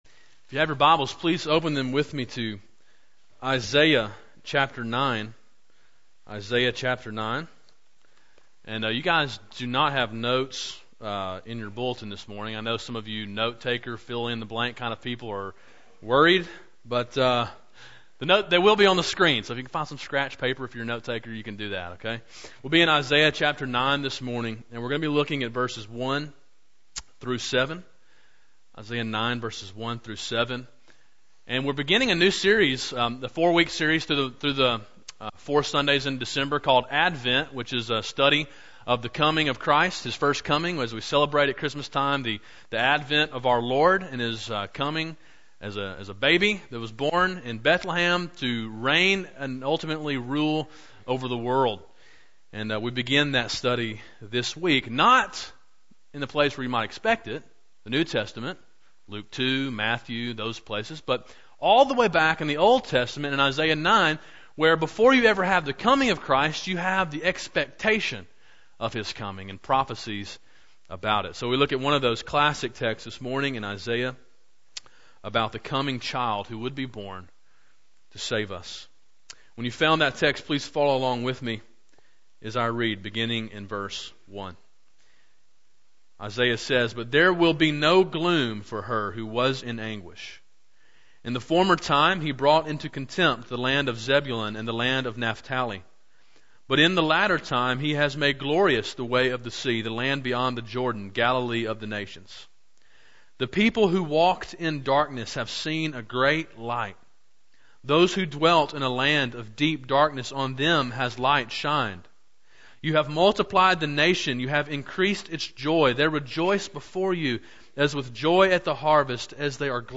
december-4-2011-morning-sermon.mp3